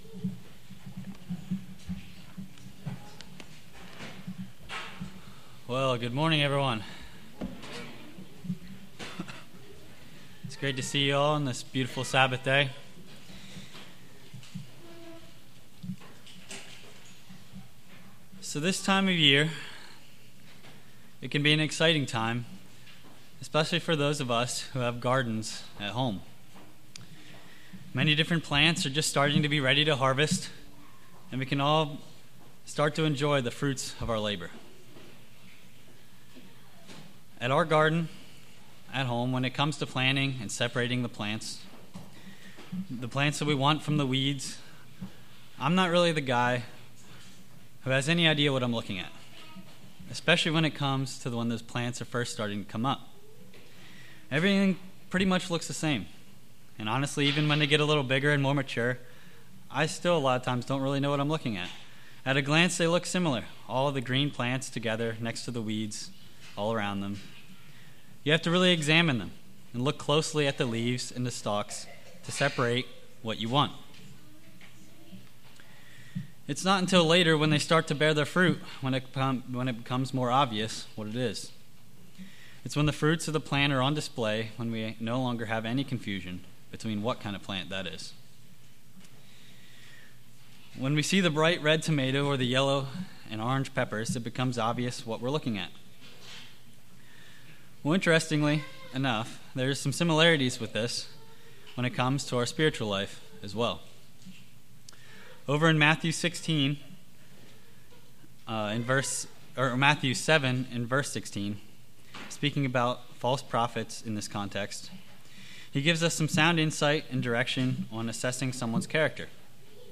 Given in Lewistown, PA